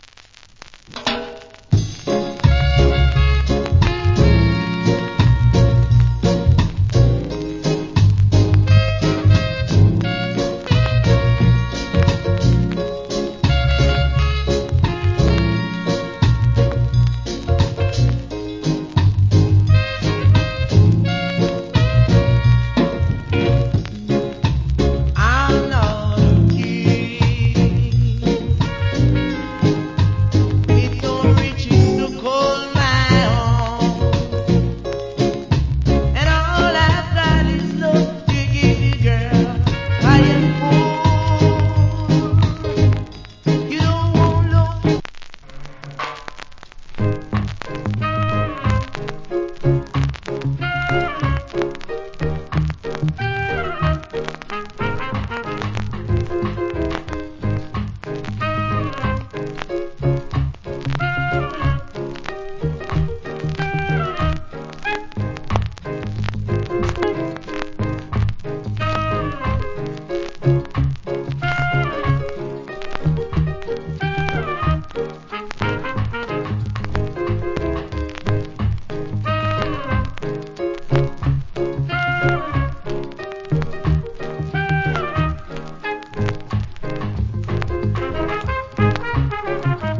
Good Rock Steady Vocal.